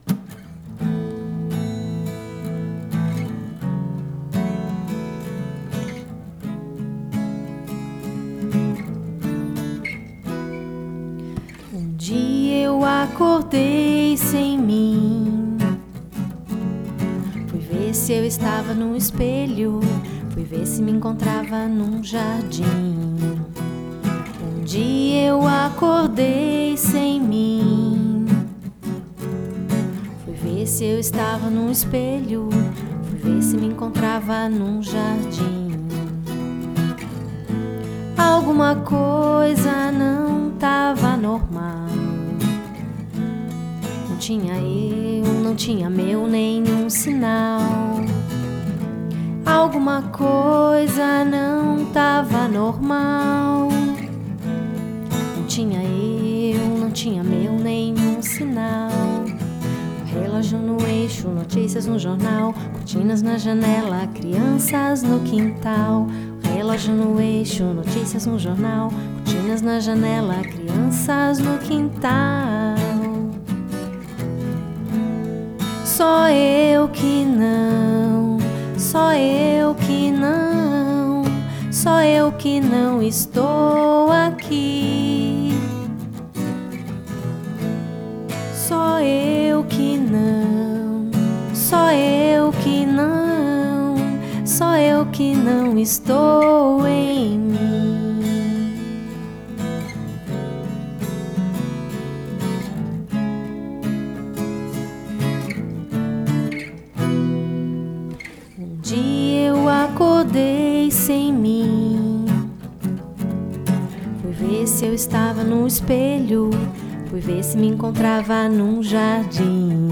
sem-mim_poema-musicado.mp3